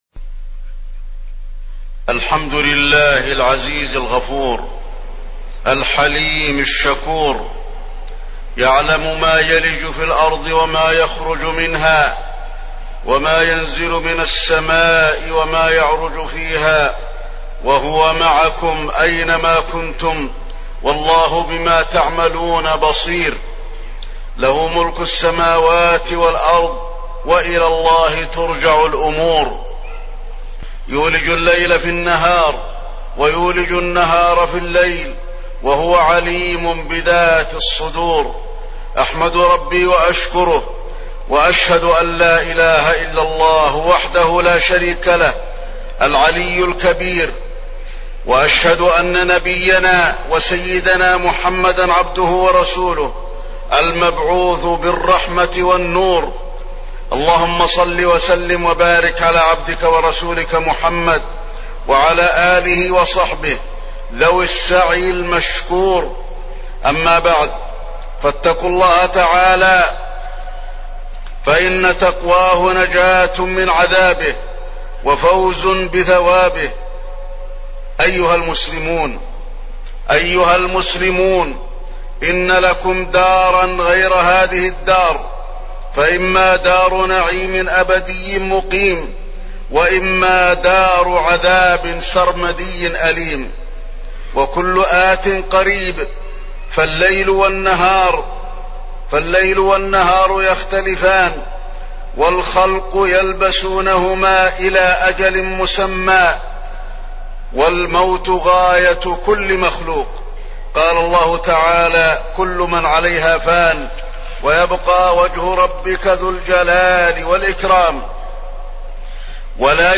تاريخ النشر ٢ ربيع الأول ١٤٣٠ هـ المكان: المسجد النبوي الشيخ: فضيلة الشيخ د. علي بن عبدالرحمن الحذيفي فضيلة الشيخ د. علي بن عبدالرحمن الحذيفي الجنة